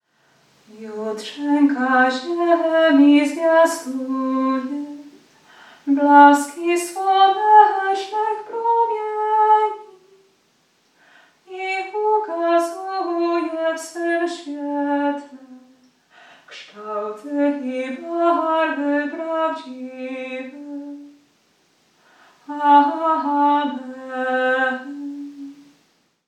Hymn_8sylab_2_Jutrzenka-ziemi-kjmqm1lp.mp3